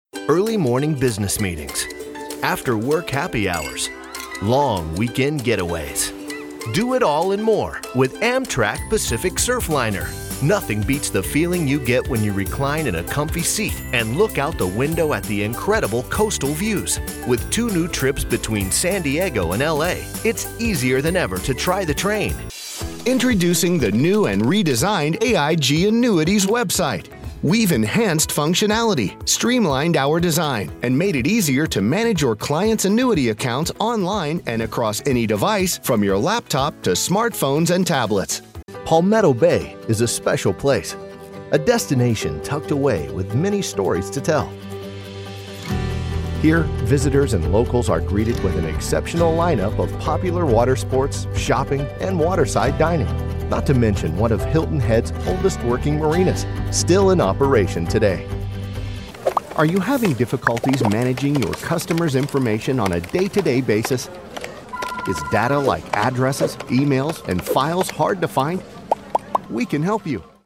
Experienced Voice Over artist for any format or project
Commercial Demo
Middle Aged